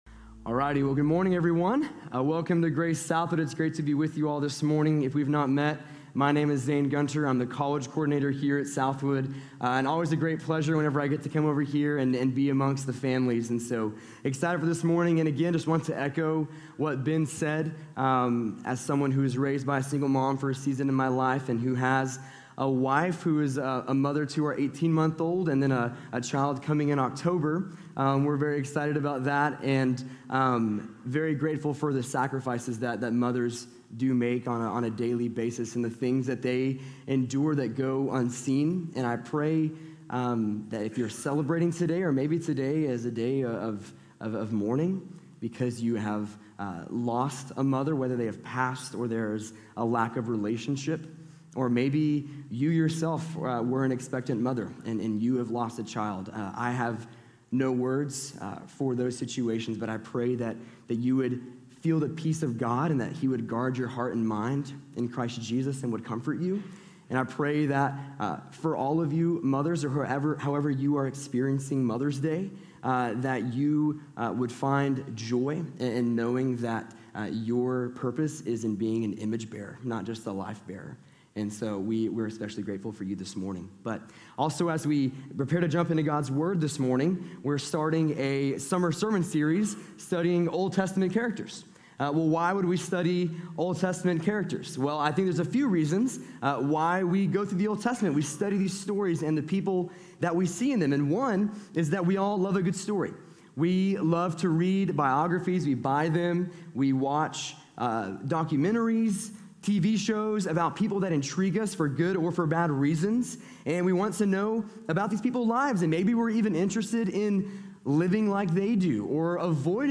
Gideon | Sermon | Grace Bible Church